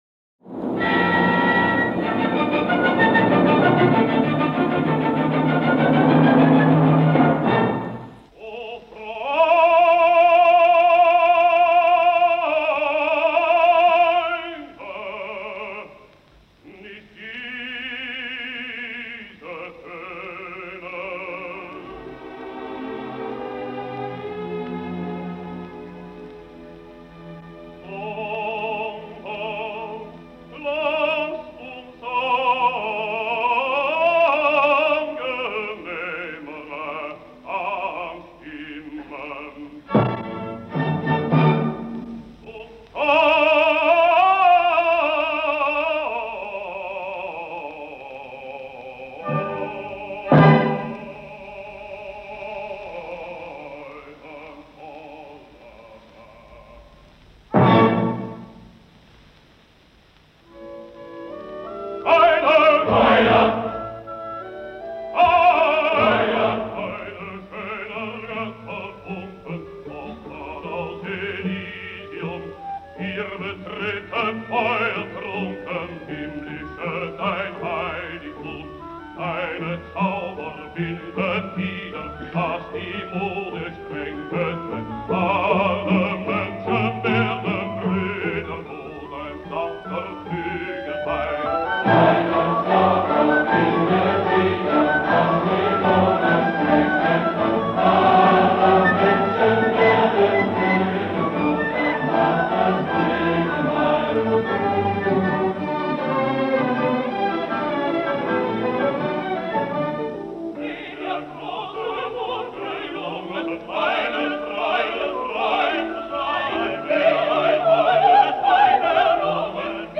2. RUDOLF WATZKE (Bass)
Berlin Philharmonic Orchestra
cond. by Wilhelm Furtwängler
(Recorded: May 1937, Live)